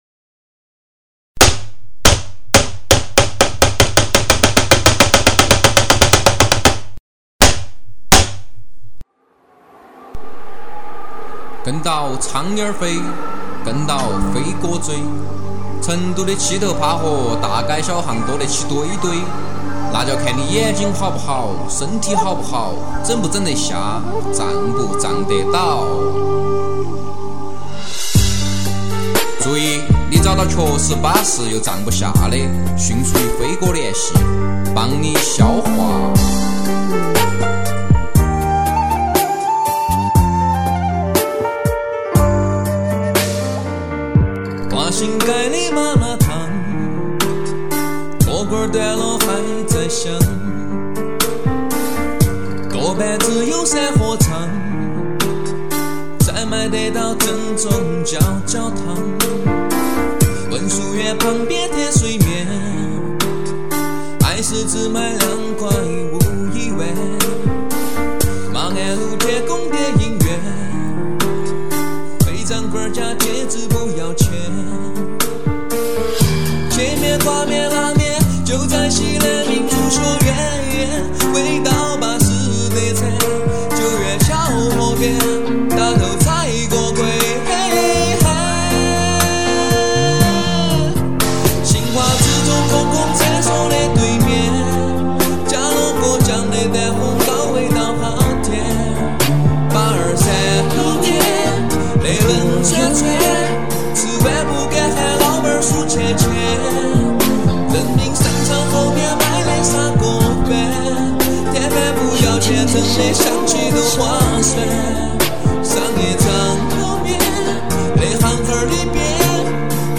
成都话真是够土够掉渣